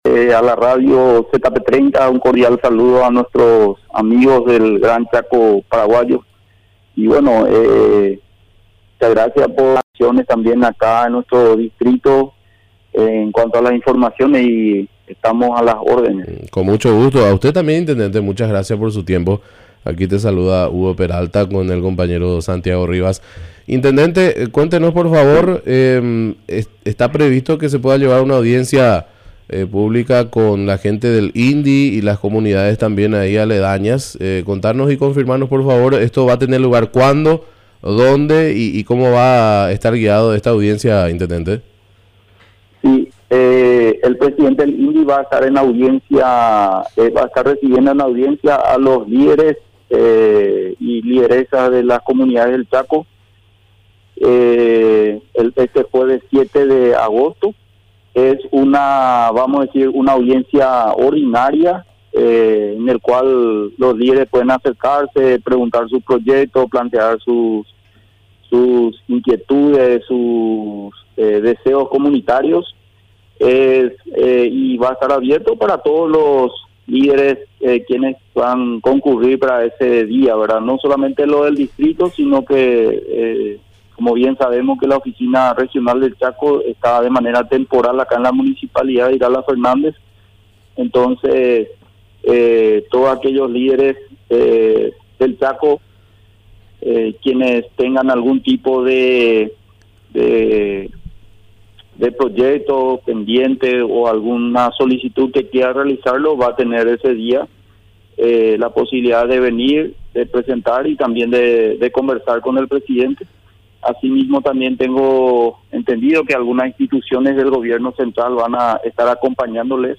Entrevistas / Matinal 610 Acarreo de agua a las comunidades indígenas y latinas Aug 05 2025 | 00:24:13 1x 00:00 / 00:24:13 Subscribe Share RSS Feed Share Link Embed